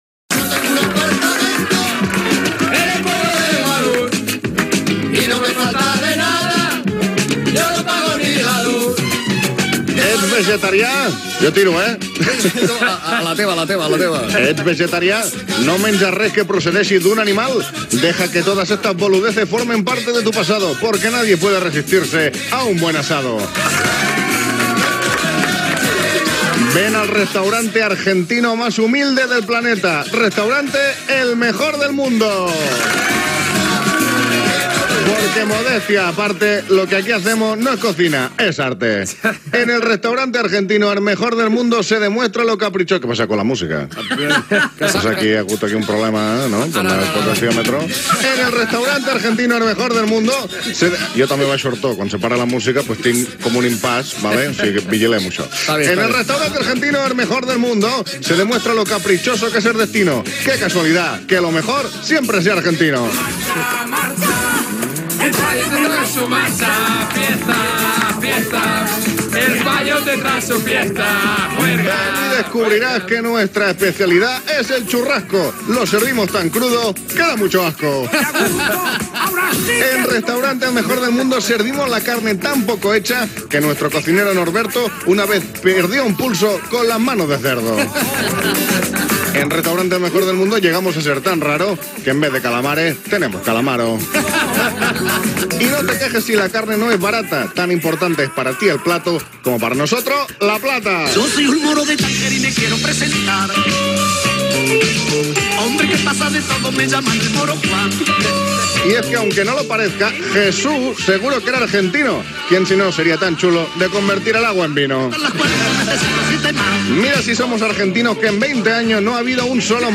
El Just (imitació de Justo Molinero) fat un anunci del Restaurante Argentino el Mejor